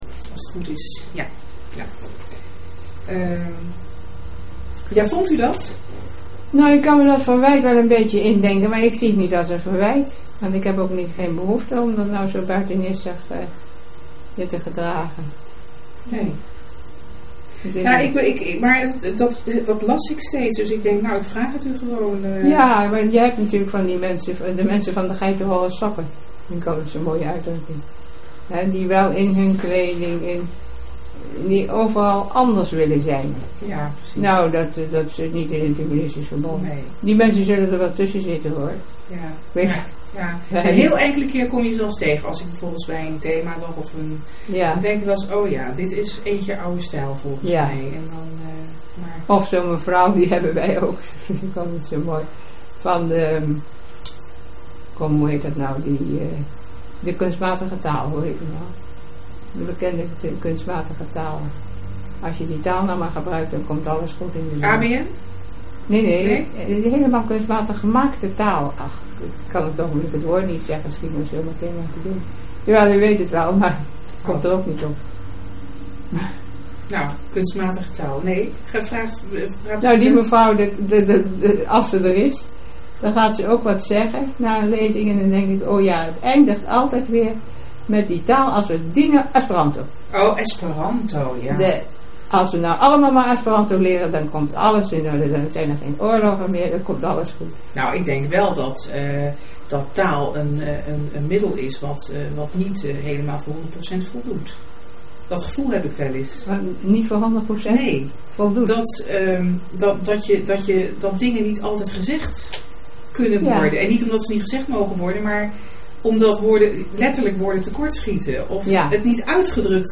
Interview Hannie Singer-Dekker deel 1 Onderwerpen : * K indertijd; * K ennismaking met het Humanistisch Verbond.